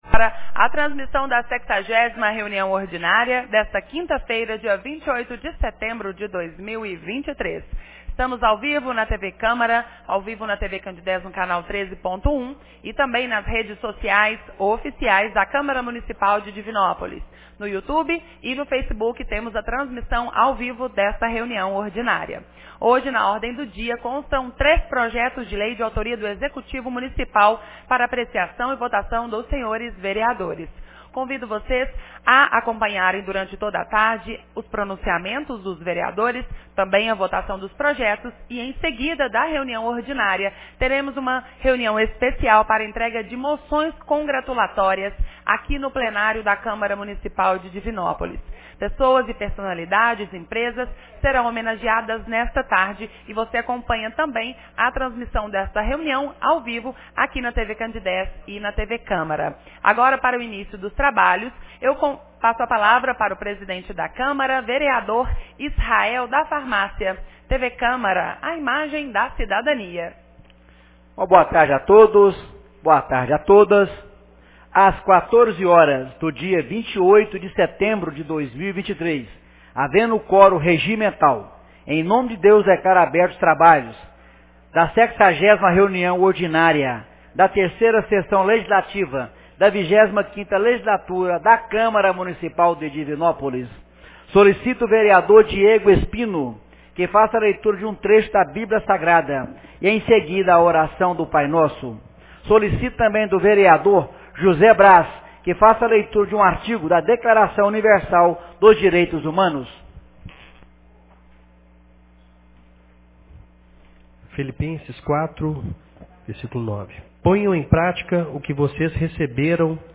60ª Reunião Ordinária 28 de setembro de 2023